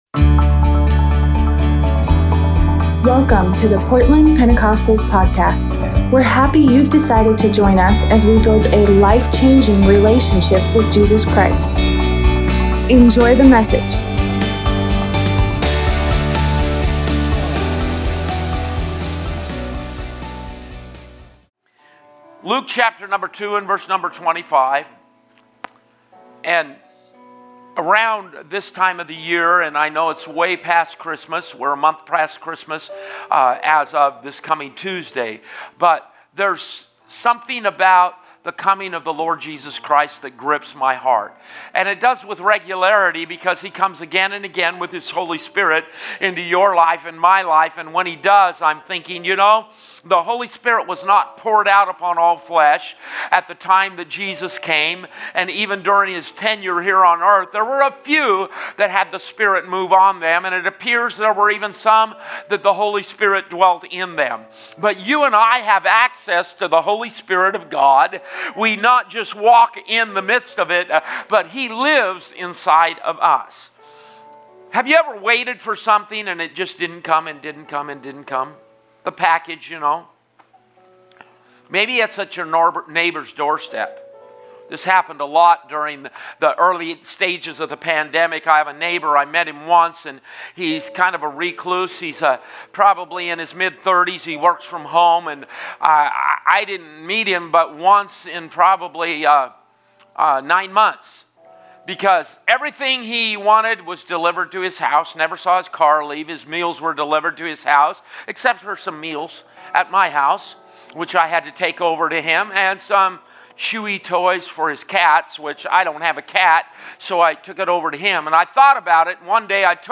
Sunday sermon